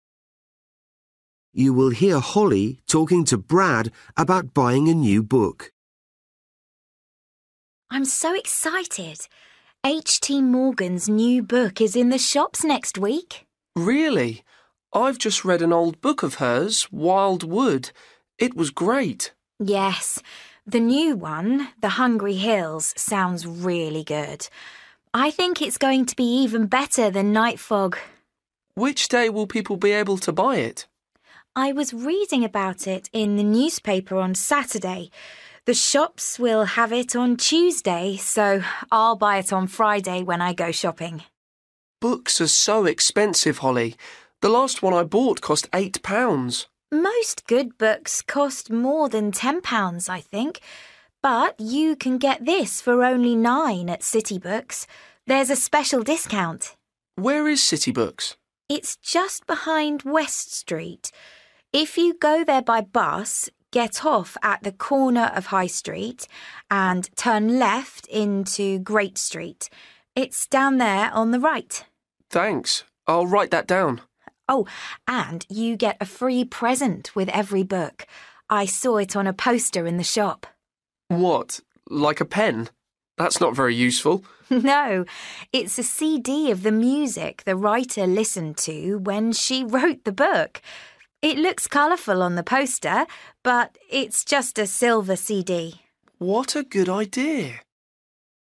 Bài tập trắc nghiệm luyện nghe tiếng Anh trình độ sơ trung cấp – Nghe một cuộc trò chuyện dài phần 42
You will hear Holly talking to Brad about buying a new book.